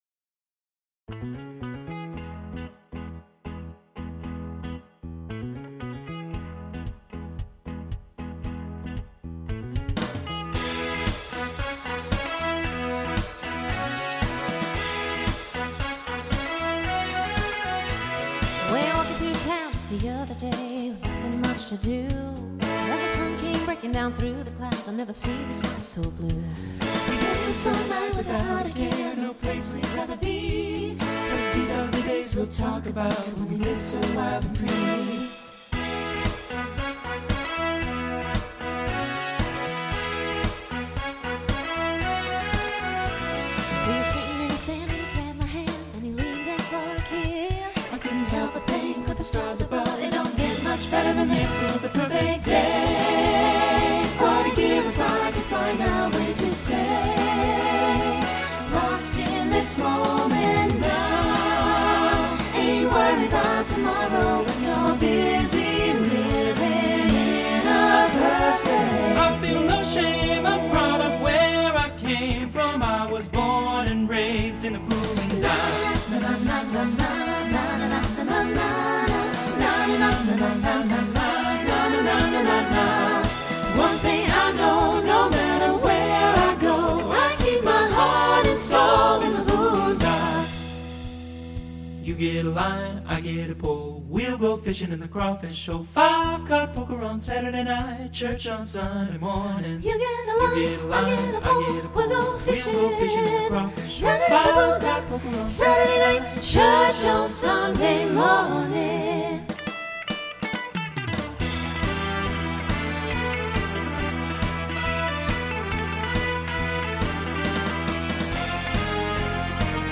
Voicing SATB Instrumental combo Genre Country , Rock